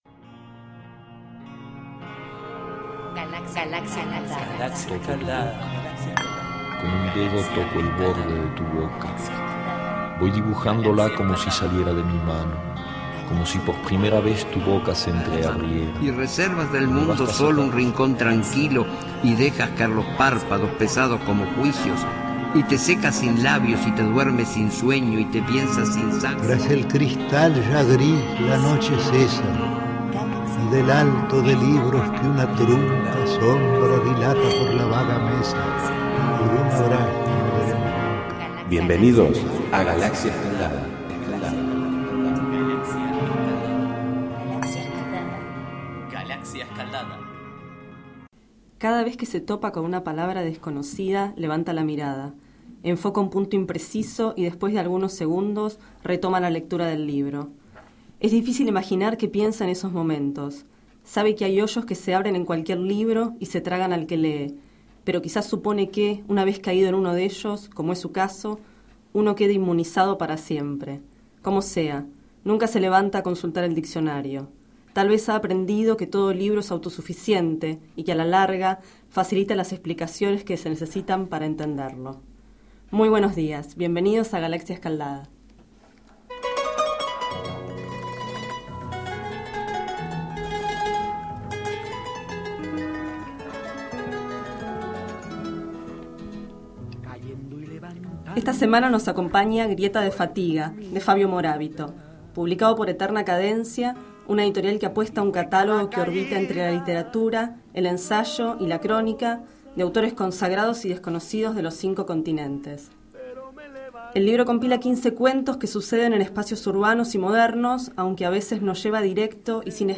Este es el 17º micro radial, emitido en los programas Enredados, de la Red de Cultura de Boedo, y En Ayunas, el mañanero de Boedo, por FMBoedo, realizado el 30 de junio 2012, sobre el libro Grieta de fatiga, de Fabio Morábito.